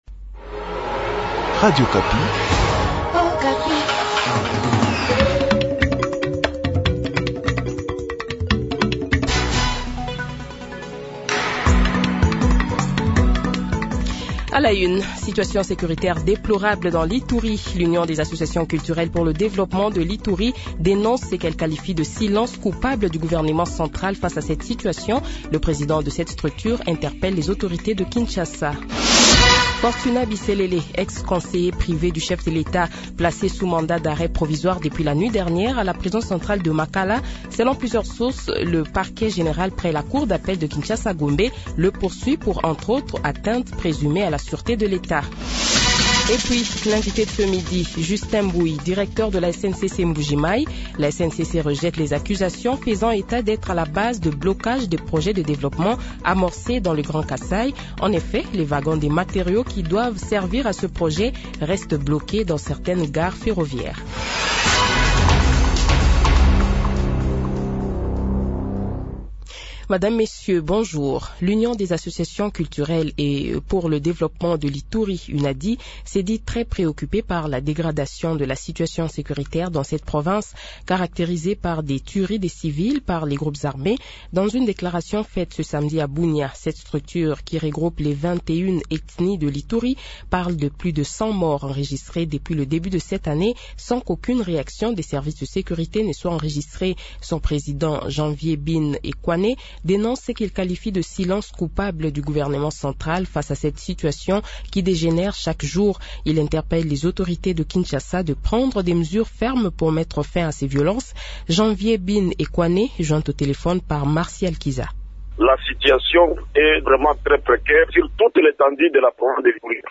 Journal Midi
Le Journal de 12h, 21 Janvier 2023 :